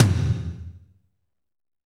Index of /90_sSampleCDs/Northstar - Drumscapes Roland/DRM_Fast Rock/TOM_F_R Toms x
TOM F RLM07R.wav